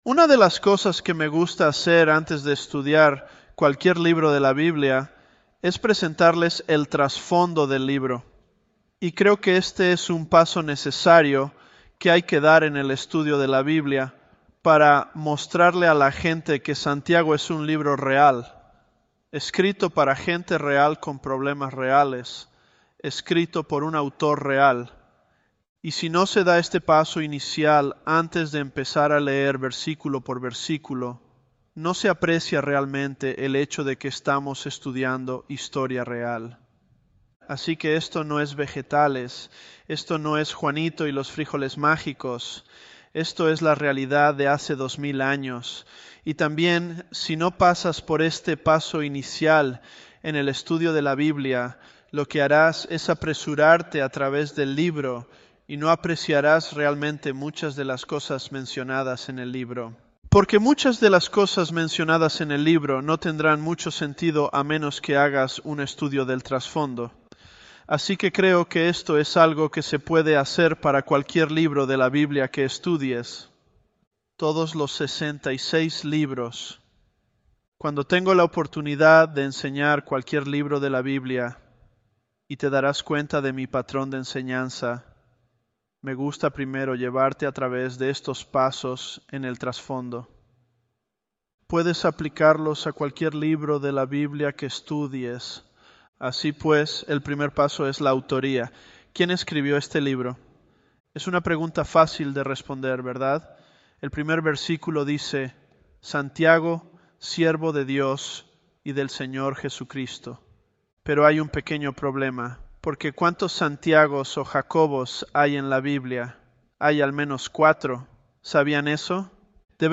Elevenlabs_James001.mp3